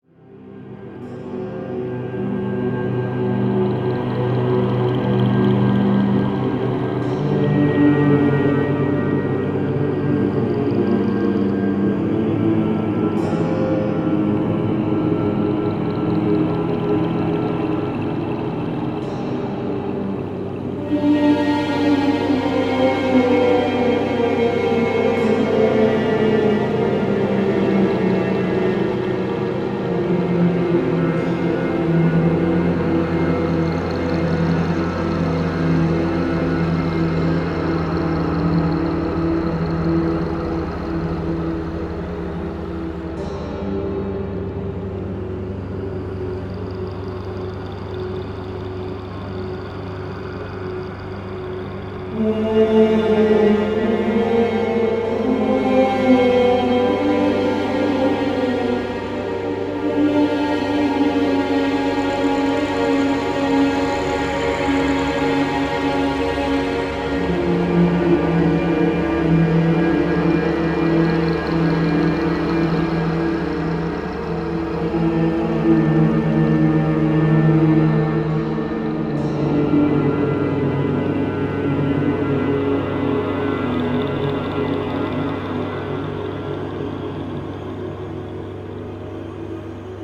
• Качество: 320, Stereo
атмосферные
пугающие
хор
мрачные
мистические
эпичные
Psybient
жуткие
Действительно криповая музыка.